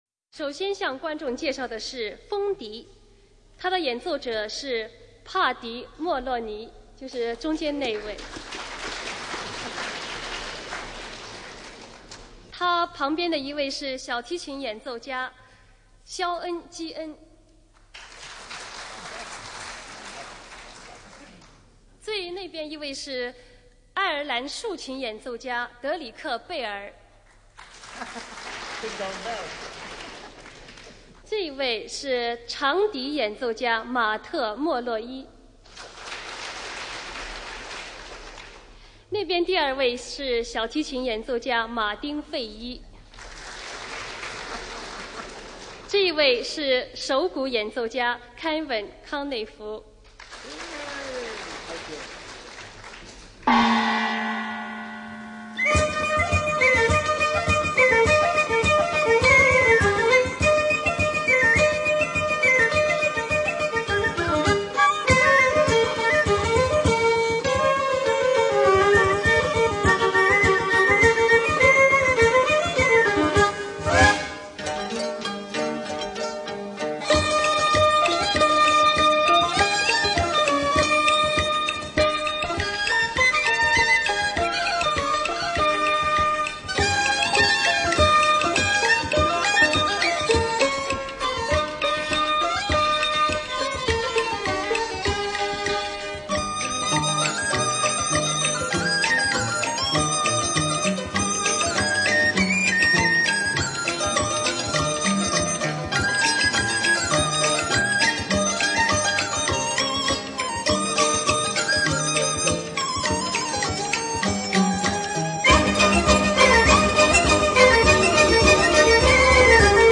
合奏中国民族乐团) PS;老外的演奏别样的味道!